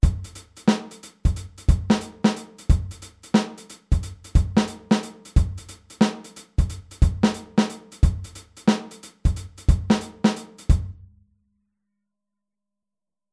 Der Handsatz beim Shufflen
Groove02b-24tel.mp3